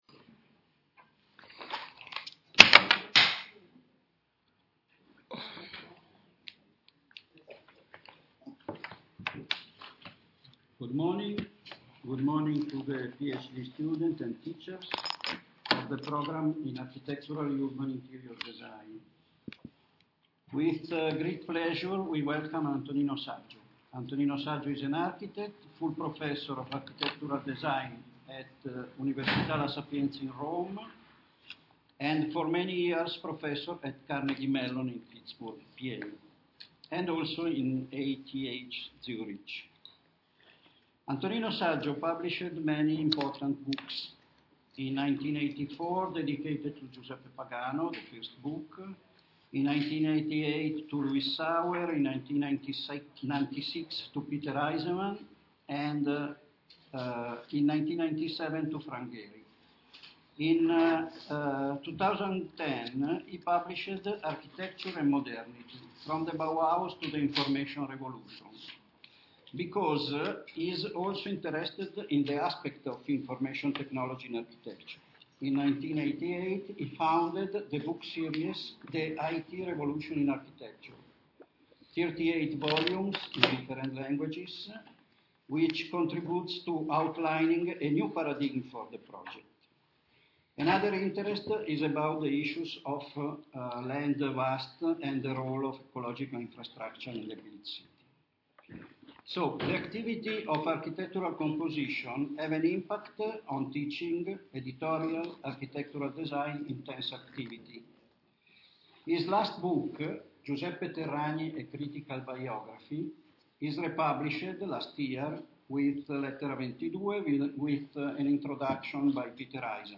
Ascolta l'audio della conferenza e della discussione